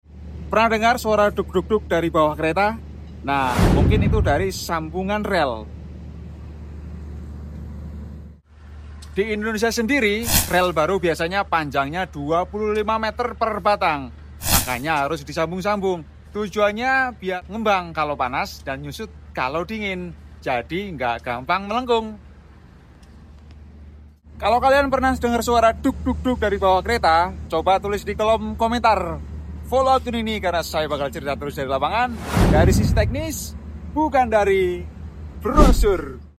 Darimana sebenarnya suara duk-duk saat kamu naik kereta api?🚂🔥🤔 Ini adalah salah satu sumber suaranya.jadi yg menyebabkan bunyi duk-duk berasal dari beberapa faktor lain,,,